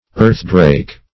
earthdrake - definition of earthdrake - synonyms, pronunciation, spelling from Free Dictionary
Search Result for " earthdrake" : The Collaborative International Dictionary of English v.0.48: Earthdrake \Earth"drake`\ ([~e]rth"dr[=a]k`), n. A mythical monster of the early Anglo-Saxon literature; a dragon.